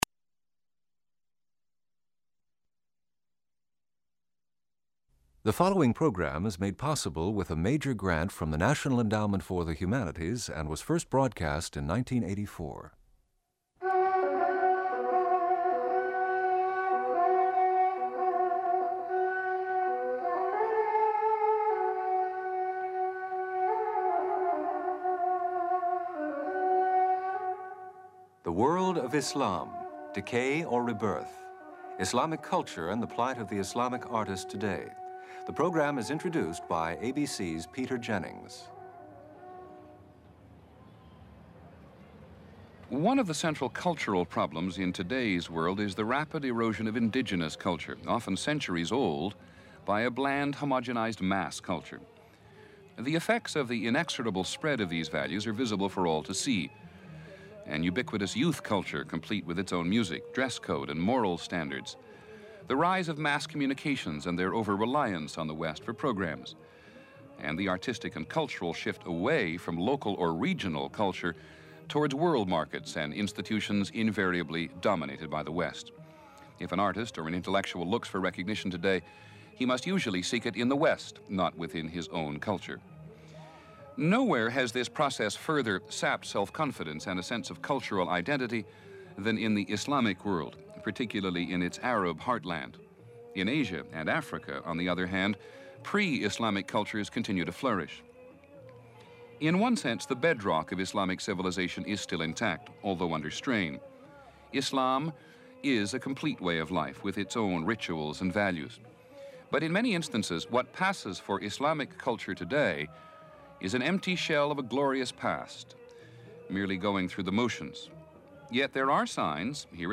People interviewed in this program